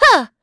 Valance-Vox_Attack7.wav